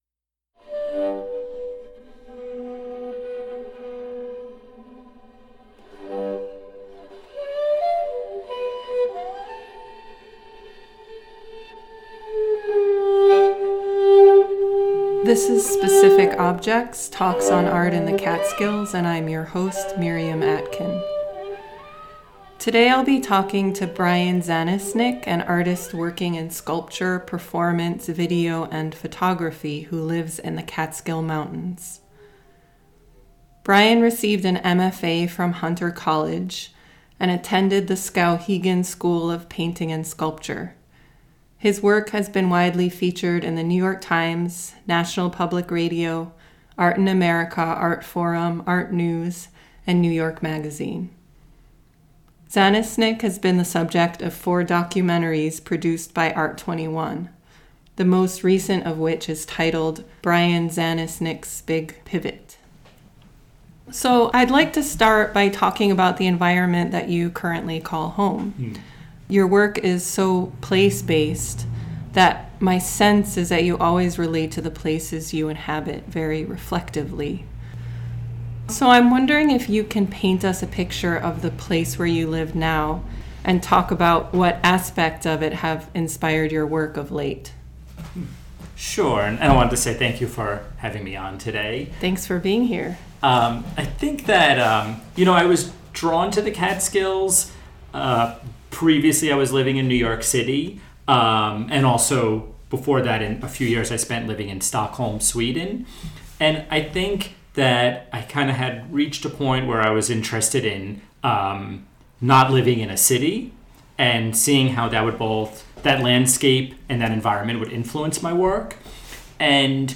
"Specific Objects" is a monthly freeform discussion